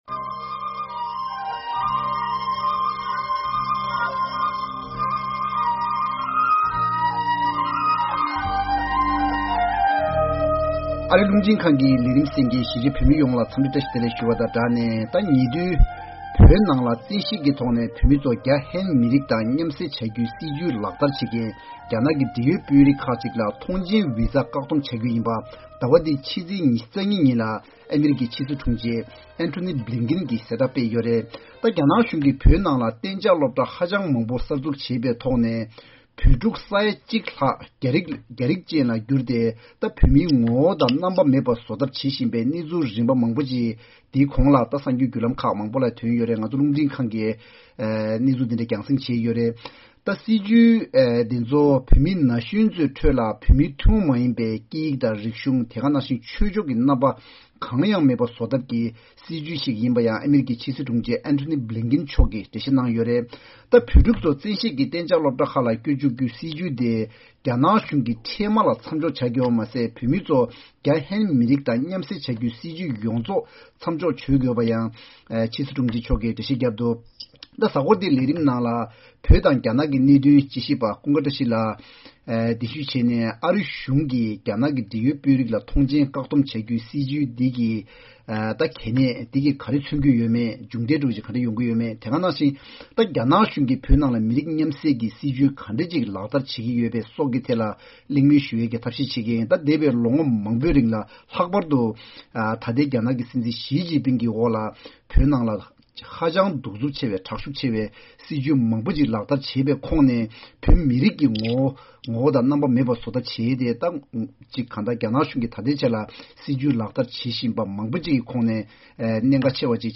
གཟའ་འཁོར་འདིའི་ལེ་ཚན་ནང་རྒྱ་ནག་གཞུང་གིས་བོད་ནང་བོད་མིའི་ངོ་བོ་རྩ་མེད་བཟོ་ཐབས་ཀྱི་སྲིད་བྱུས་ངན་པ་ལག་བསྟར་བྱེད་སྟངས་དང་འདིའི་ཤུགས་རྐྱེན་སོགས་ཀྱི་གླེང་མོལ་ཞུས་པ་དེ་གསན་རོགས་གནང་།